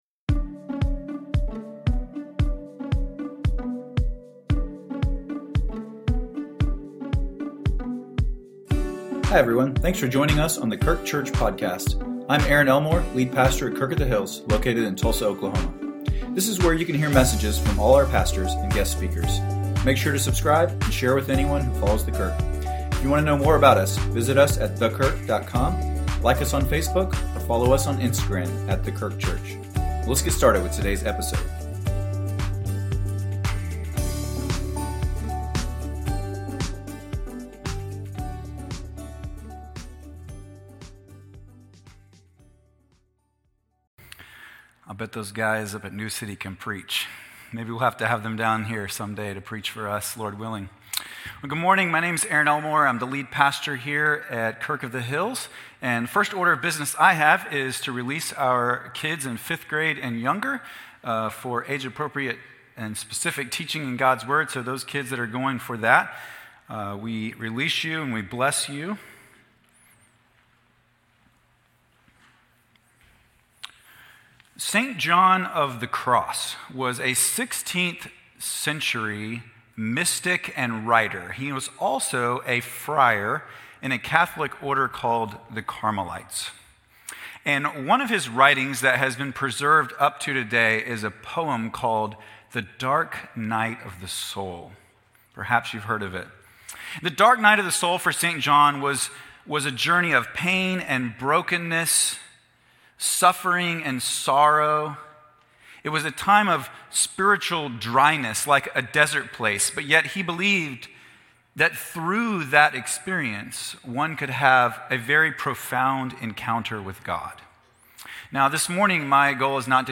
A message from the series "Mercy in the Mourning."